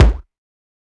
MB Kick (40).wav